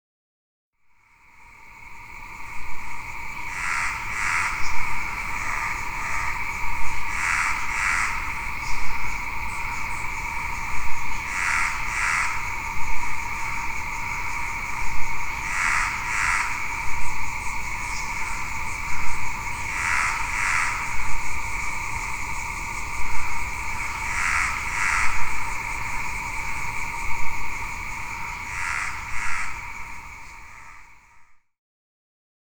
The cicadas buzz in the woods throughout the day; in the evening, the frog chorus breaks out into pulsing serenades of courting.
Here’s a sample of the frog chorus at night.
frogchorus.mp3